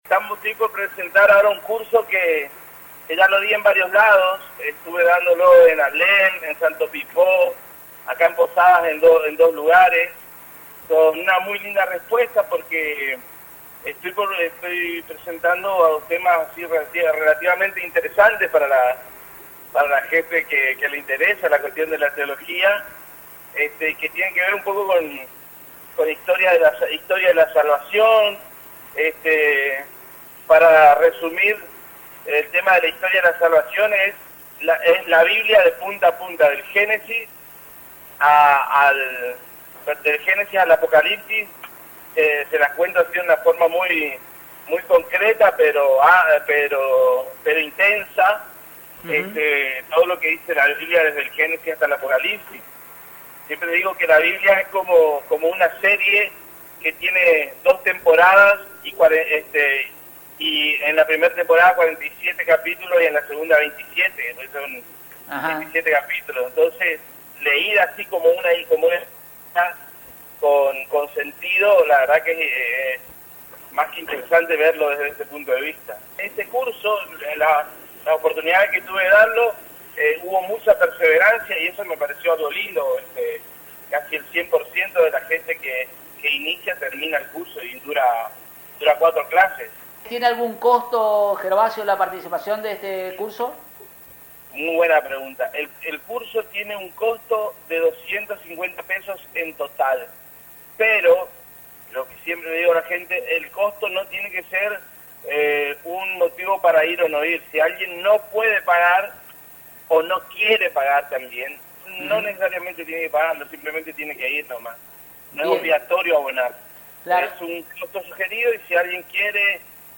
En charla telefónica